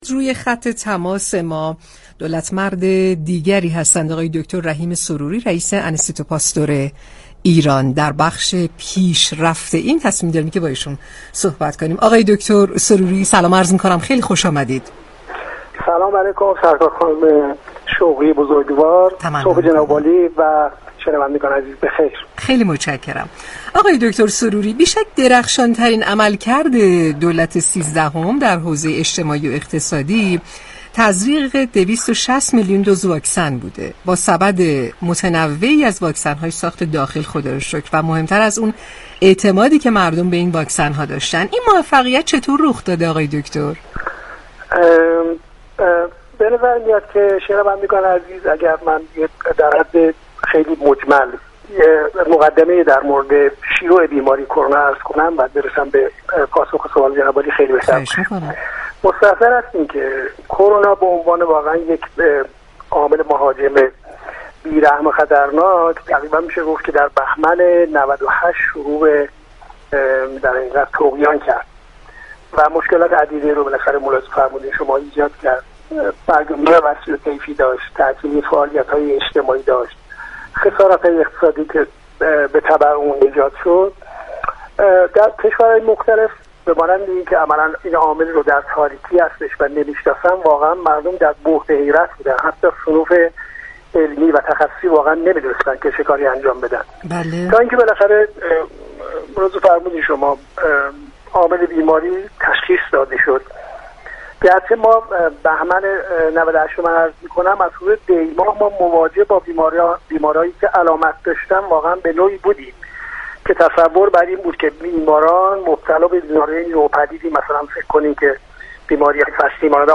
به گزارش پایگاه اطلاع رسانی رادیو تهران، دكتر رحیم سروری سرپرست انستیتو پاستور ایران به مناسبت هفته دولت با برنامه «شهر آفتاب» رادیو تهران گفت‌وگو كرد.